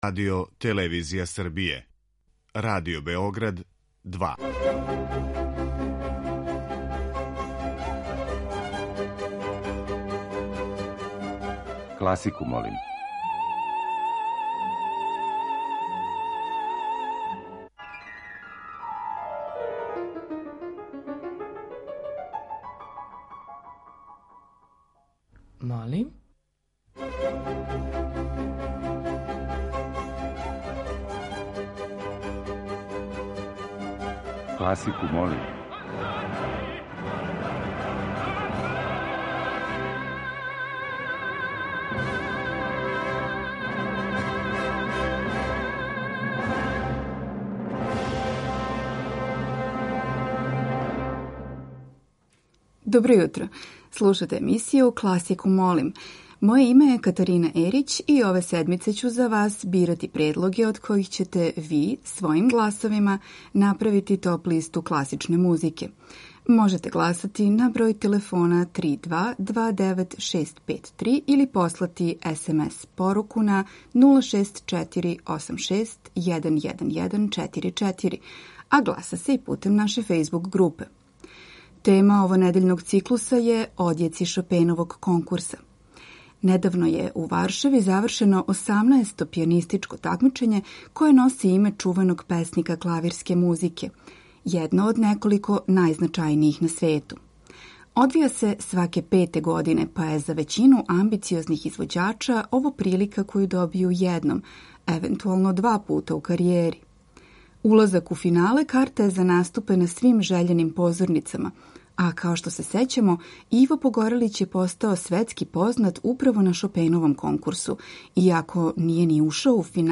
Снимци најбољих такмичара на последњем Шопеновом конкурсу у Варшави.